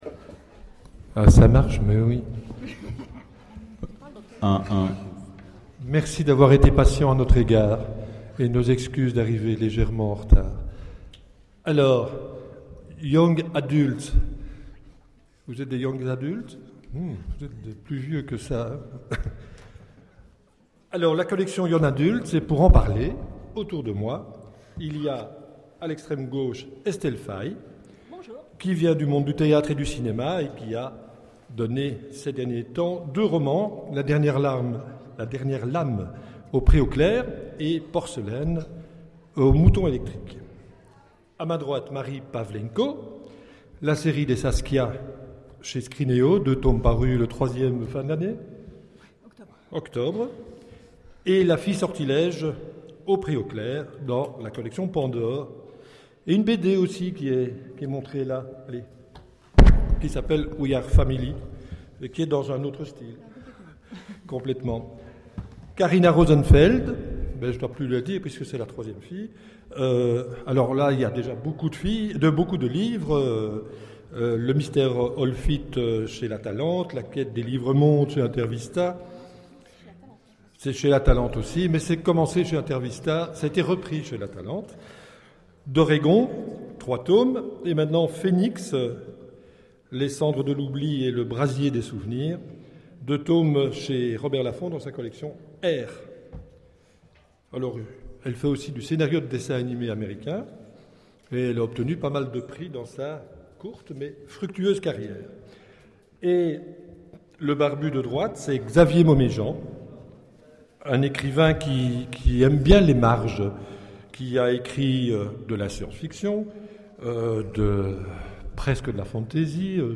Imaginales 2013 : Conférence Les collections young adult